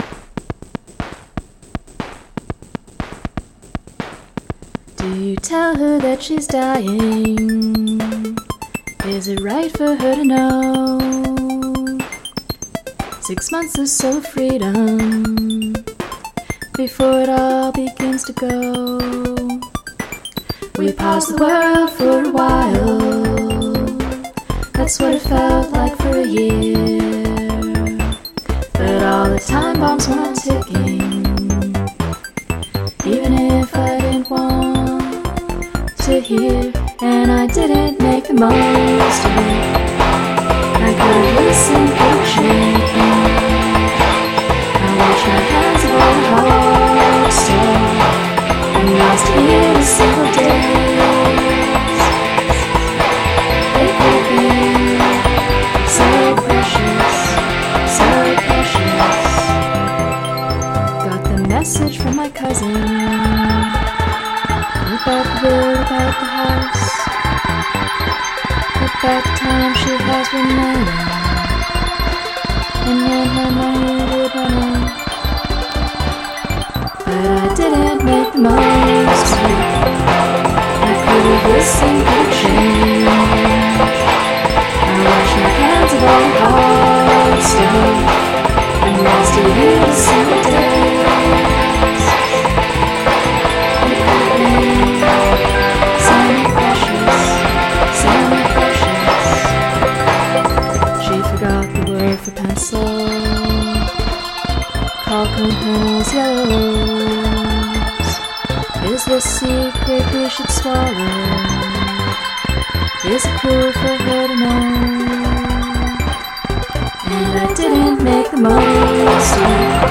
Gradual emphasis of repetitions
Overpowers the melody almost totally.